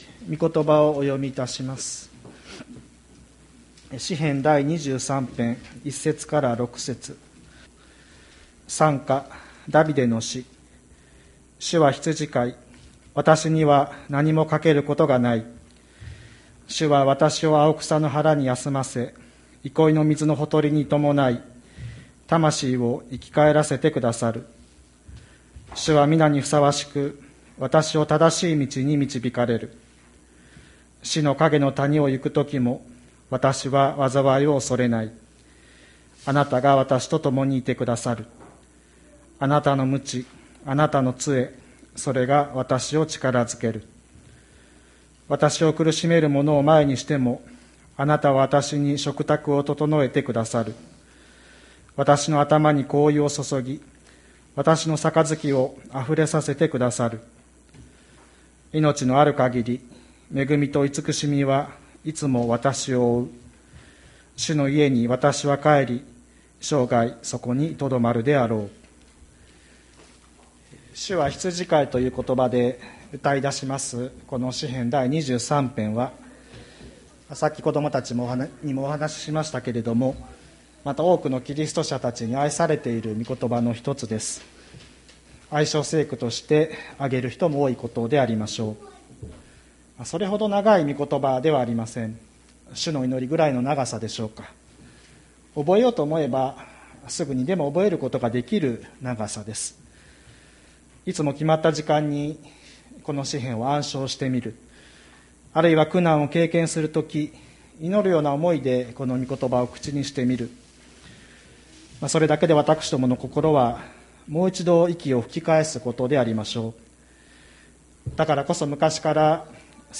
2021年01月03日朝の礼拝「主はわたしの羊飼い」吹田市千里山のキリスト教会
千里山教会 2021年01月03日の礼拝メッセージ。